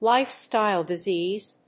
音節lífestyle disèase